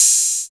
70 OP HAT.wav